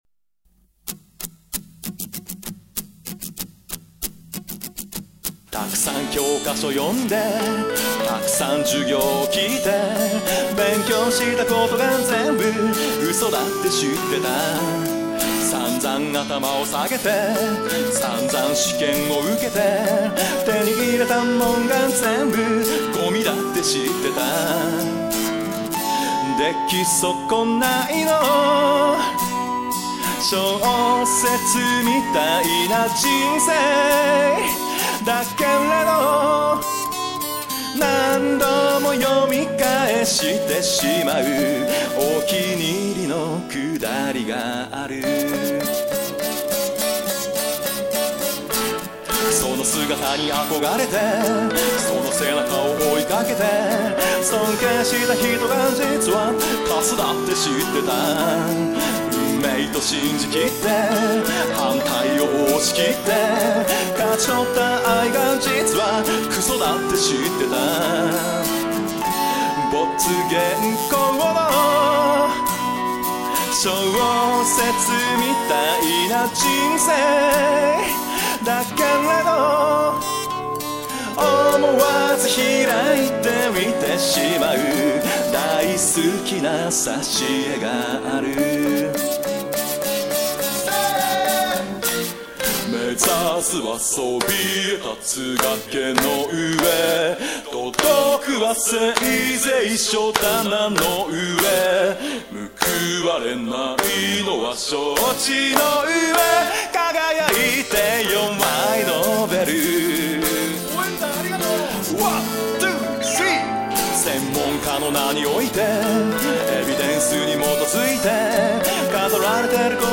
大学時代に見た応援団のエールを、ちょいと楽曲に取り入れてみました。